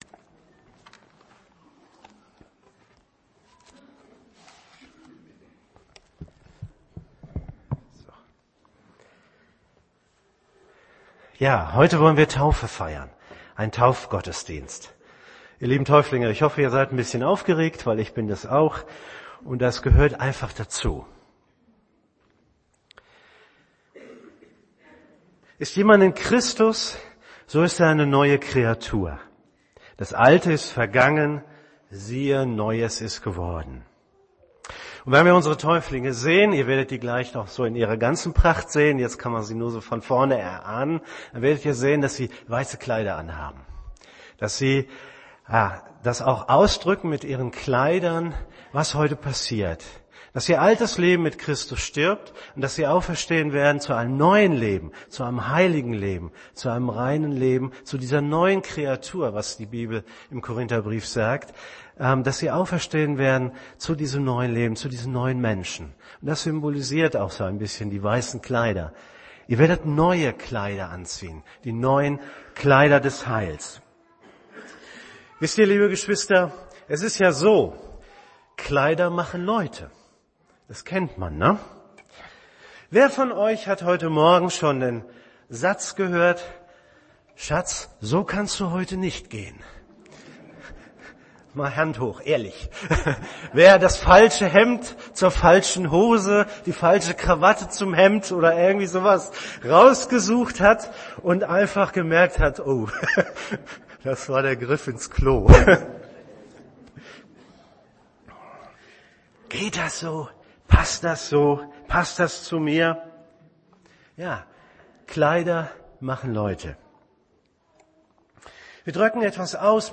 "Kleider machen Christen" - der Kleiderschrank Gottes (Taufgottesdienst)